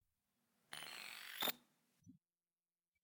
infocomputershutdown.ogg